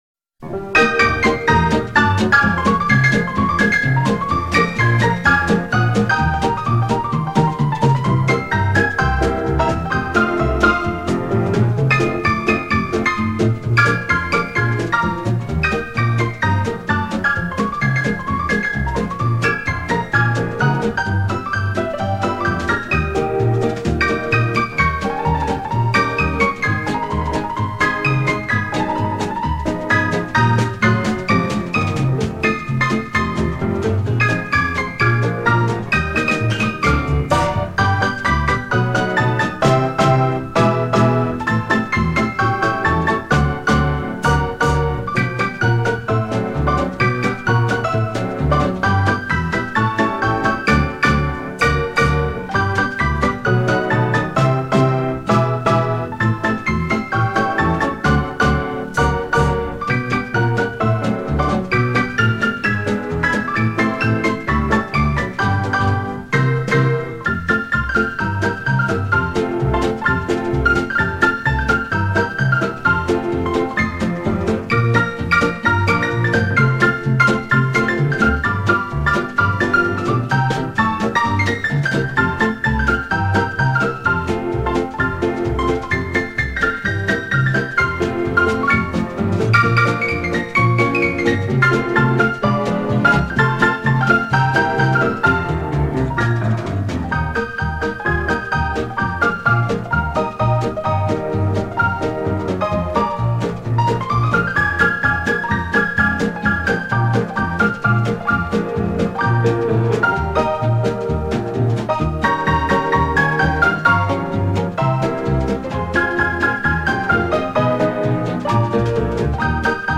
(инструментальная пьеса)
Попурри состоит из 10 мелодий:
(instrumental)